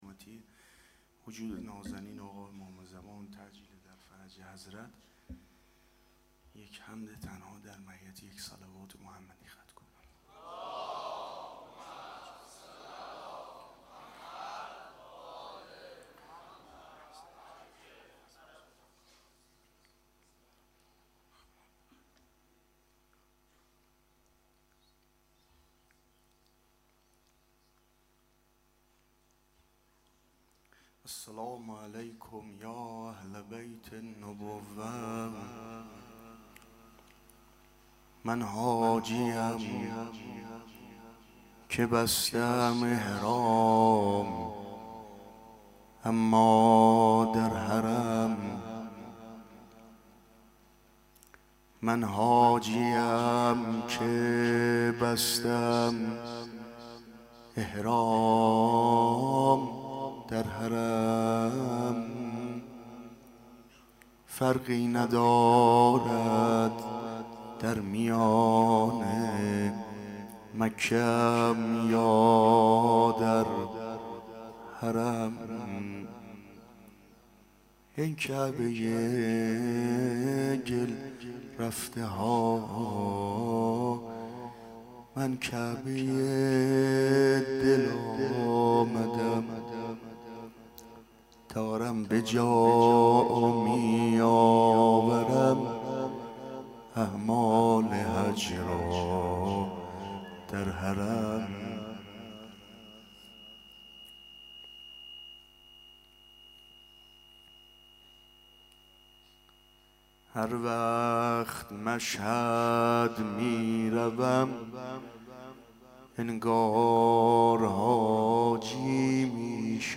این مراسم با سخنرانی
مداحی